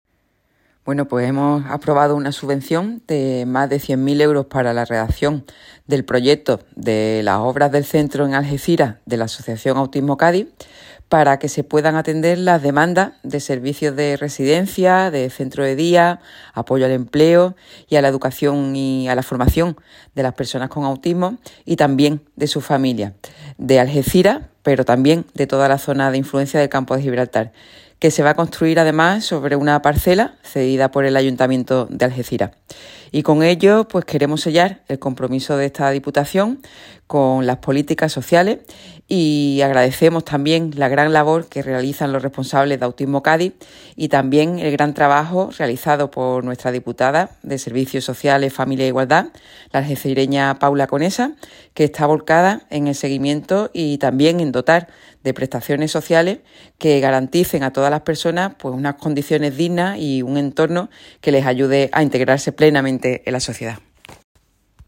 Almudena Martínez valora esta subvención que permitirá crear el nuevo servicio en una parcela cedida por el Ayuntamiento